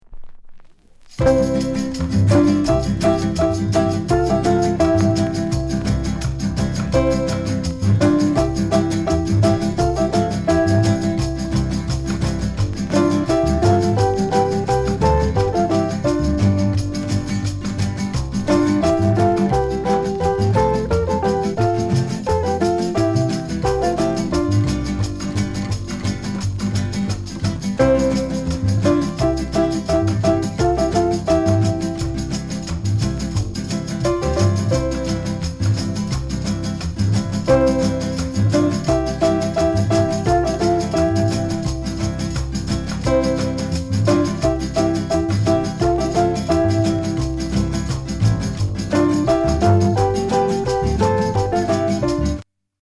NICE INST